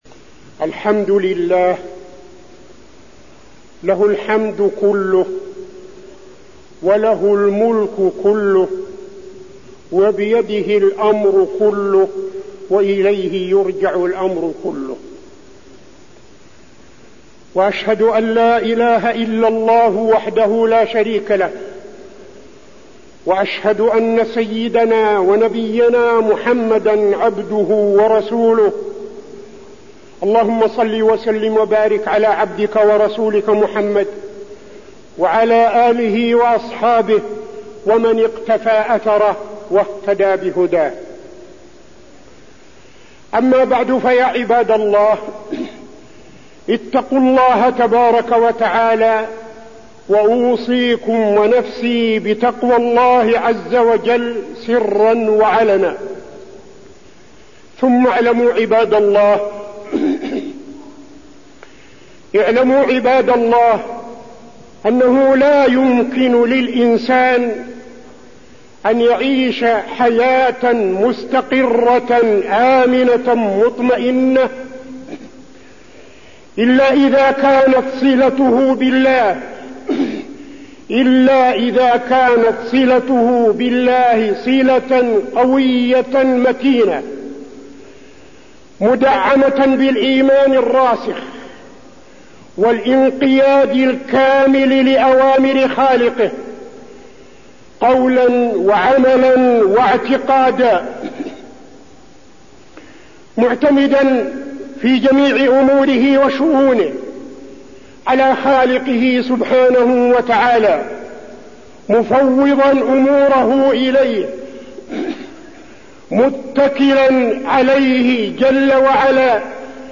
تاريخ النشر ٩ صفر ١٤٠٥ هـ المكان: المسجد النبوي الشيخ: فضيلة الشيخ عبدالعزيز بن صالح فضيلة الشيخ عبدالعزيز بن صالح الصلة الراسخة بالله